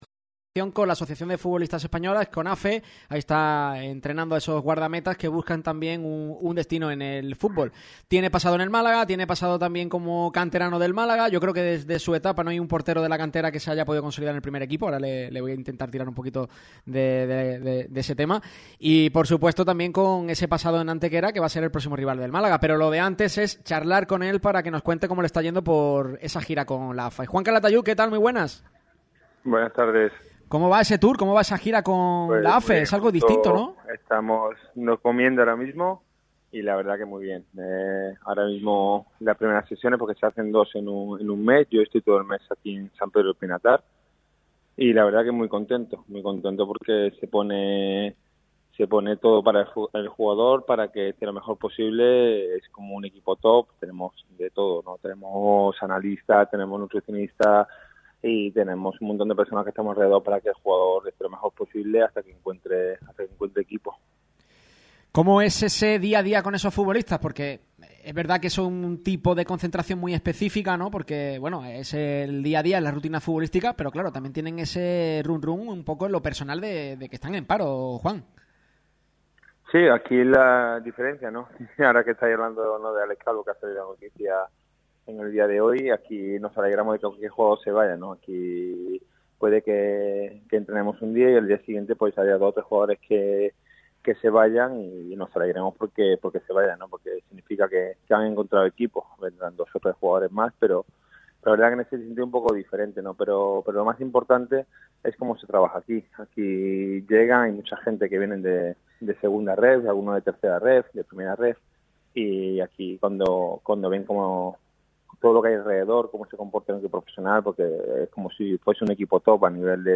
El ex portero del Málaga CF ha estado en directo hoy con Radio MARCA Málaga y ha aprovechado para hablar sobre determinadas cuestiones.